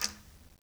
Quinto-Tap1_v1_rr1_Sum.wav